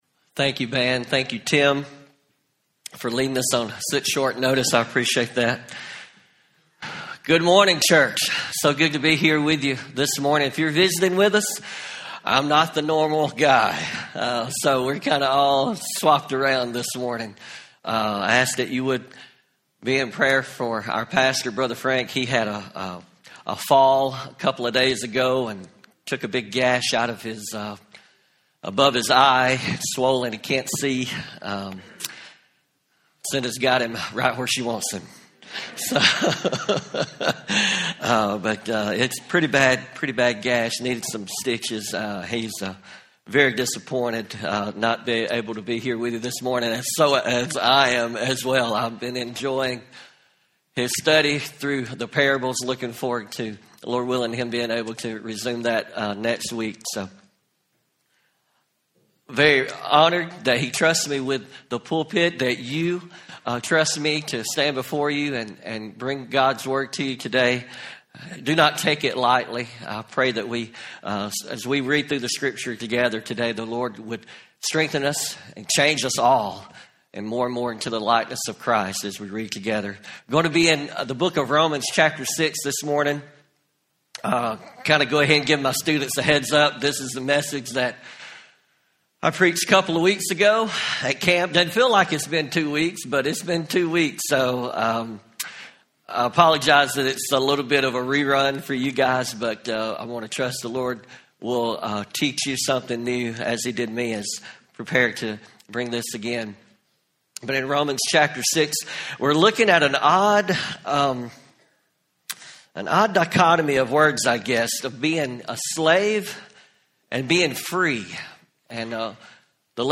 Home › Sermons › Freedom As Christ’s Slave